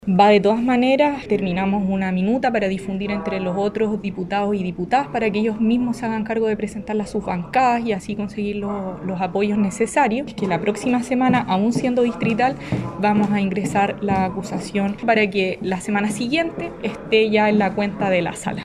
En tanto, la diputada de Comunes, Camila Rojas, dijo que la próxima semana se hará oficial la acusación contra el ministro de educación, y ya está circulando una minuta con los argumentos para que lo conozcan todas las bancadas.